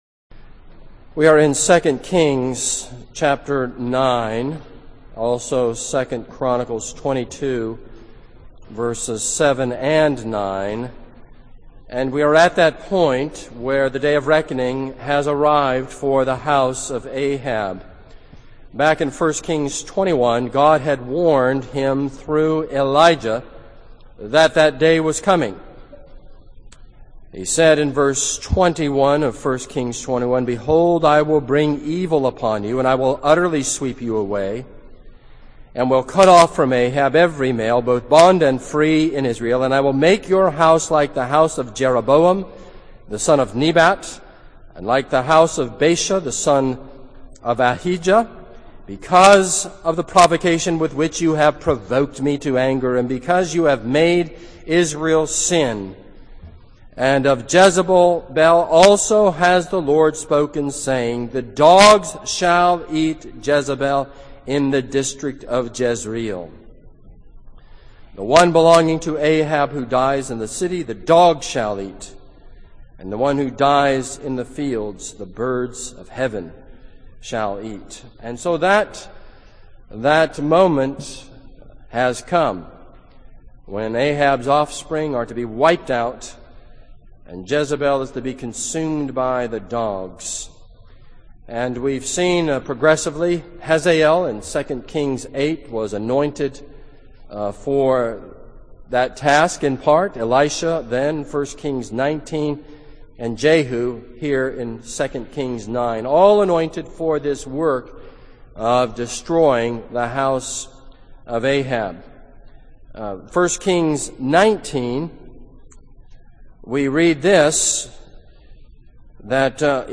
This is a sermon on 2 Kings 9.